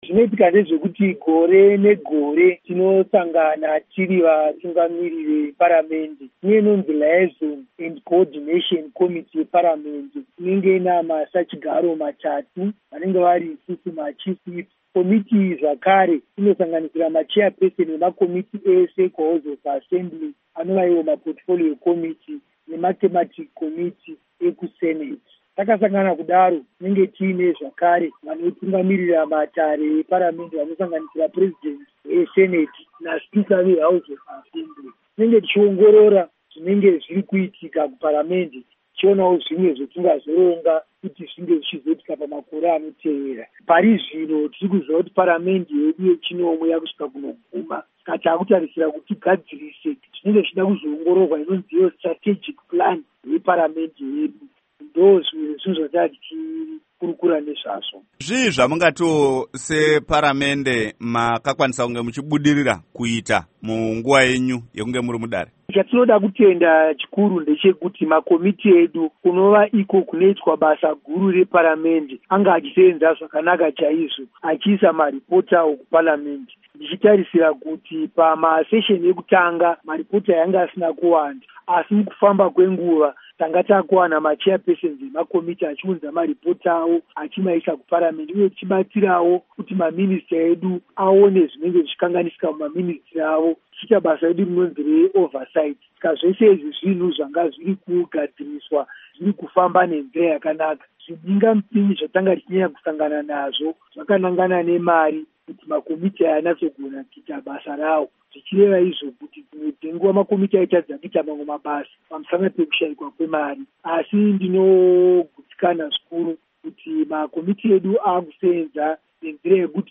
Hurukuro naVaInnocent Gonese